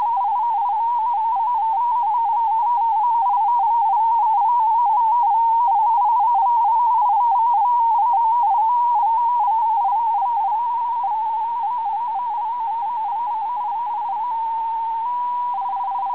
Alcatel Coquelet-8 audio samples
COQUELET-8 MFSK AUDIO SAMPLES All material Copyright © 1998 - 2008 No content on this website may be used or published without written permission of the author! Coquelet-8 system with 13.3 Bd Coquelet-8 system with 26.67 Bd back to MFSK-systems page